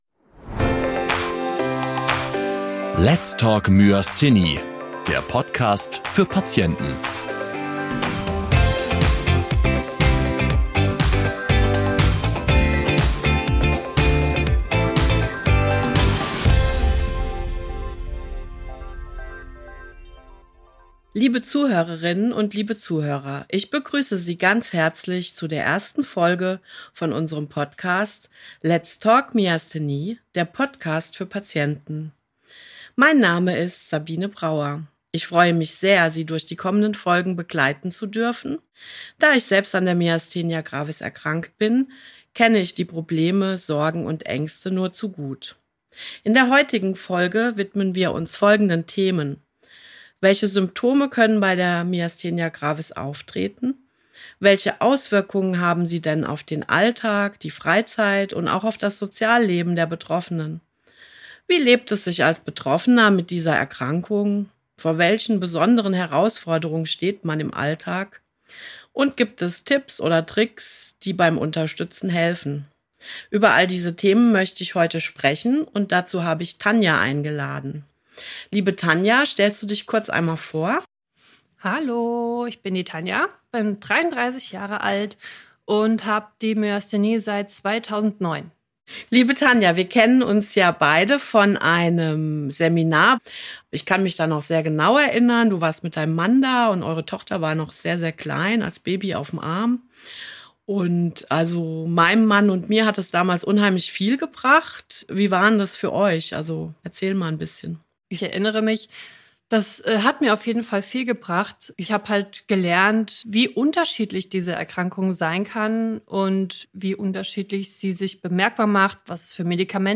Myasthenie-Patientin Gast